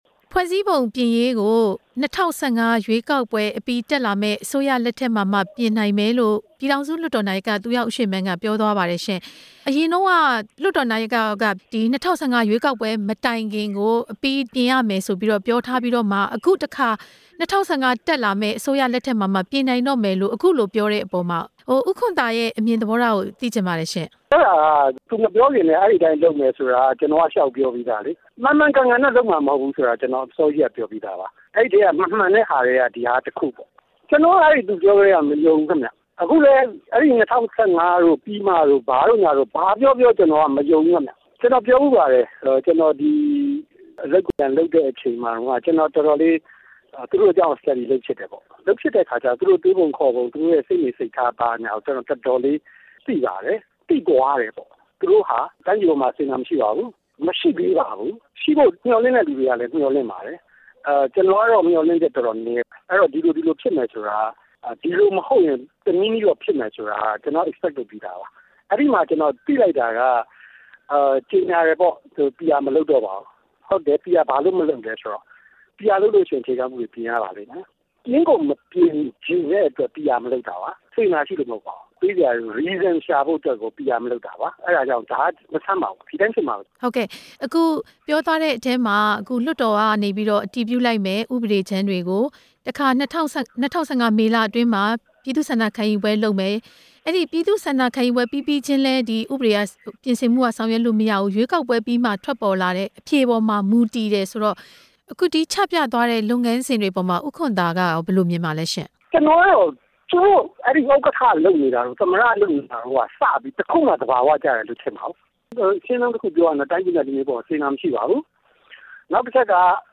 နေပြည်တော် လွှတ်တော်မှာ သတင်းထောက်တွေရဲ့ မေးမြန်းချက်ကို ဒေါ်အောင်ဆန်းစုကြည်က အခုလို ဖြေကြားခဲ့တာပါ။